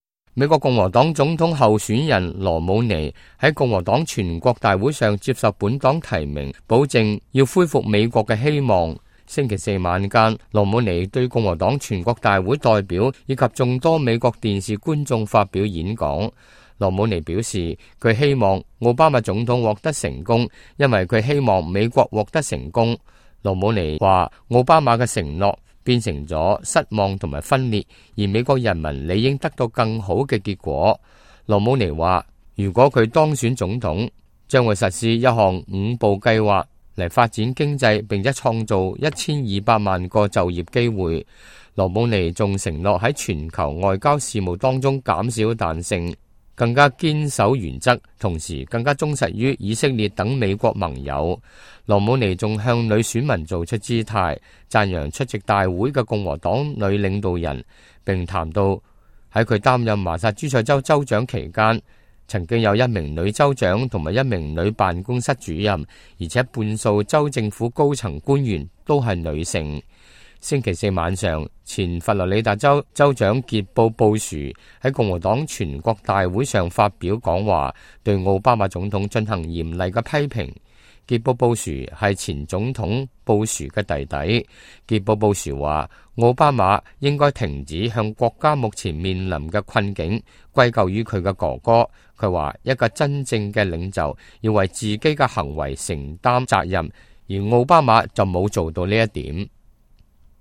美國共和黨總統候選人羅姆尼在共和黨全國大會上接受本黨提名﹐保證要“恢復美國的希望”。